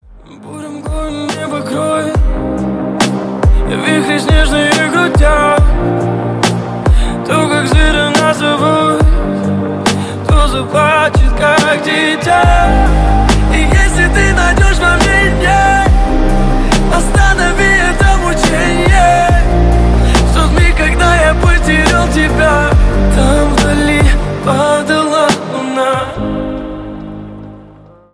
• Качество: 128, Stereo
мужской вокал
лирика
нежные